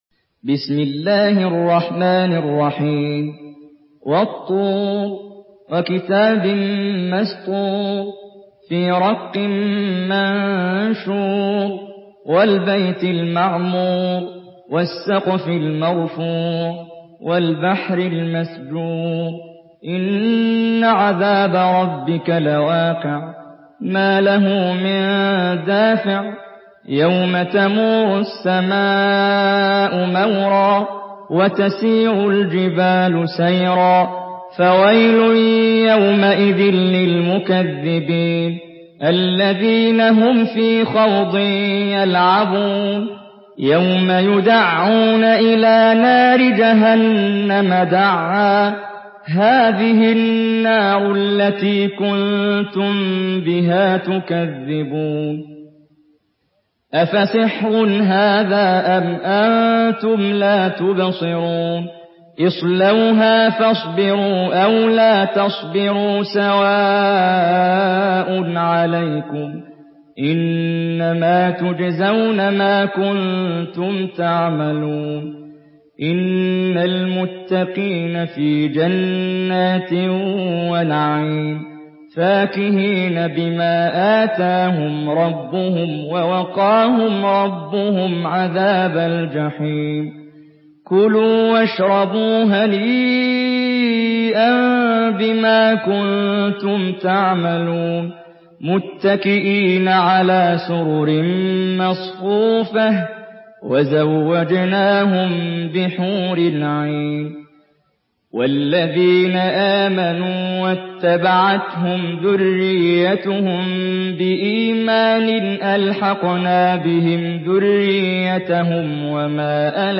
Surah আত-তূর MP3 by Muhammad Jibreel in Hafs An Asim narration.
Murattal Hafs An Asim